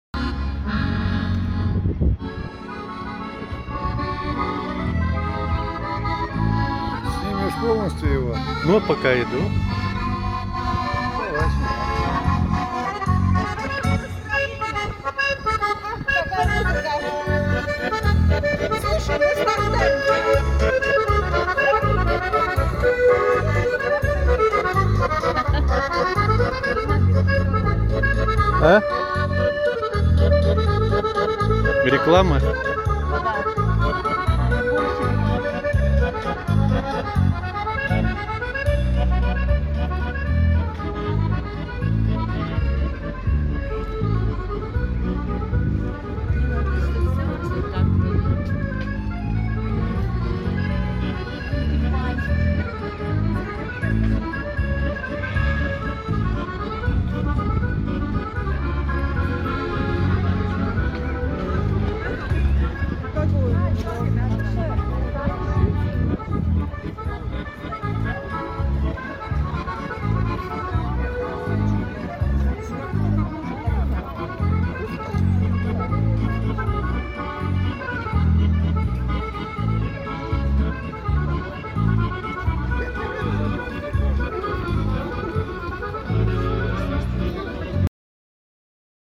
Кисловодск. Курортный бульвар. Уличные музыканты, как воздух апреля 2013.
fragment-live.mp3